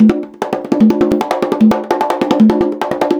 150BONGO 8.wav